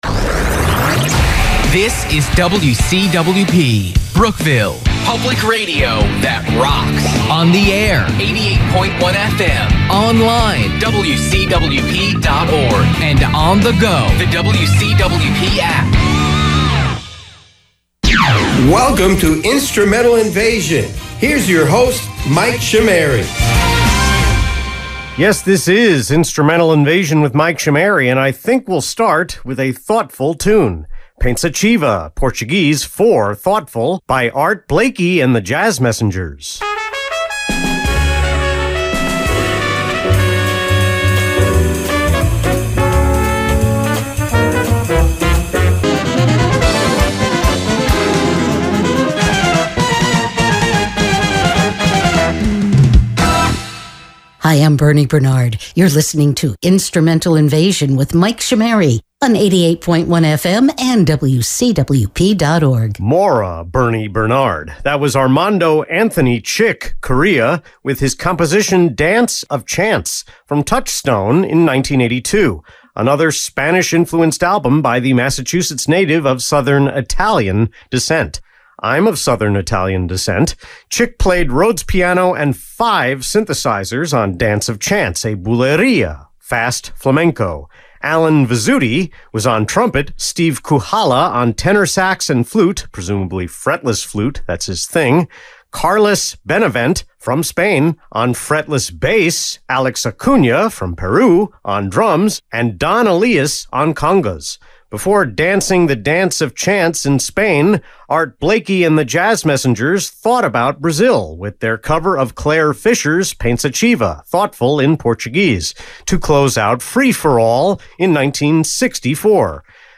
This is a detailed multimedia recap (photos, videos, audio) of the 2025 WCWP Homecoming Weekend on Long Island's 88.1 FM, WCWP and WXBA.